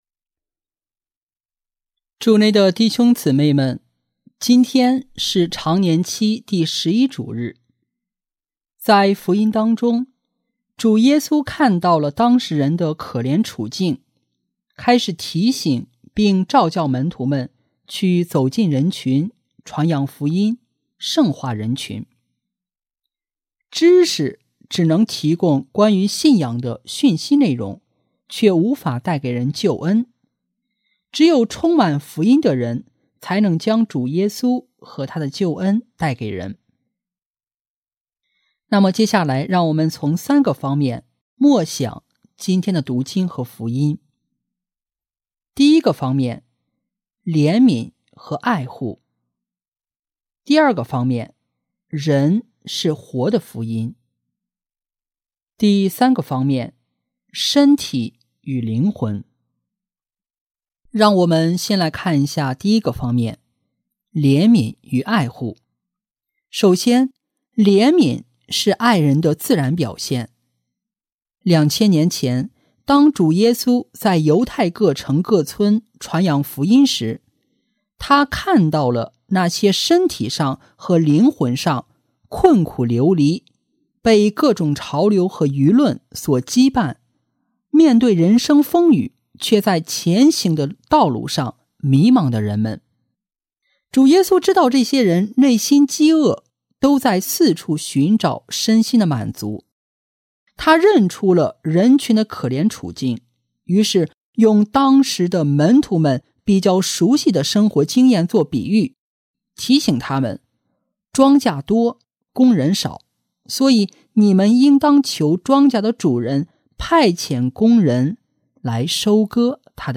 【主日证道】| 蒙召传福音（甲-常年期第11主日）